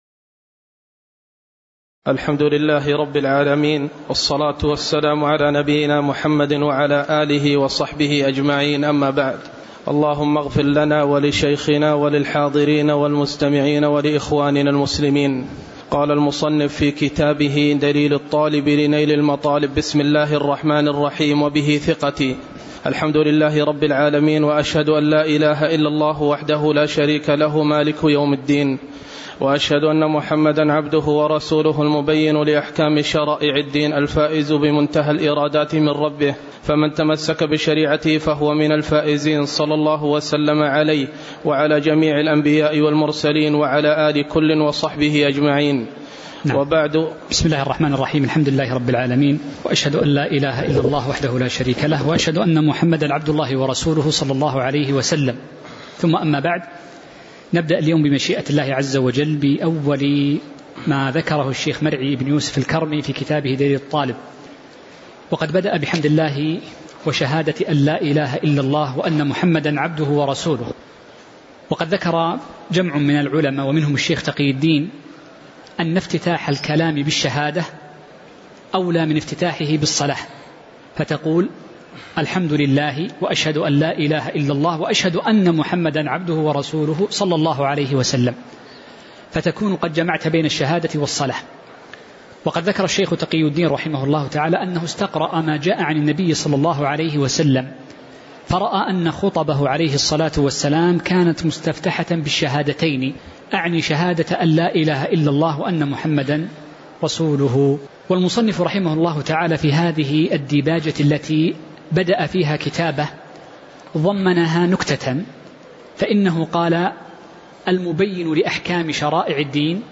تاريخ النشر ٢ ذو القعدة ١٤٤٠ هـ المكان: المسجد النبوي الشيخ